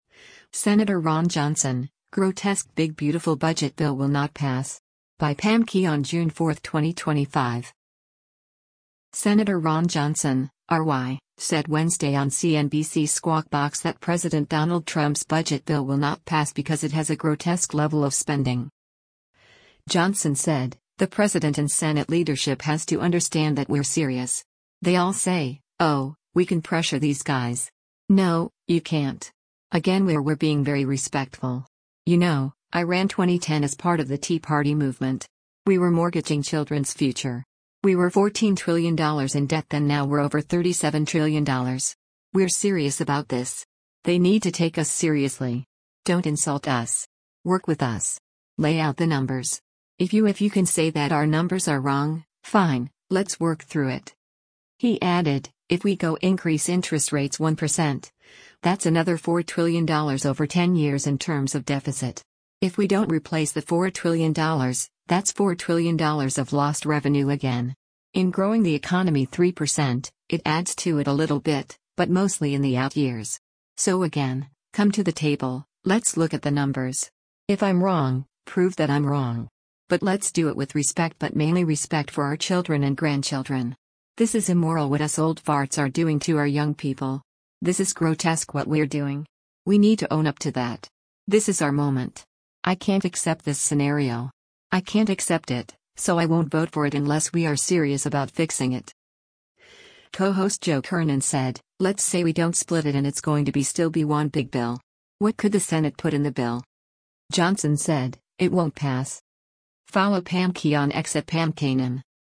Sen. Ron Johnson (R-WI) said Wednesday on CNBC’s “Squawk Box” that President Donald Trump’s budget bill will not pass because it has a “grotesque” level of spending.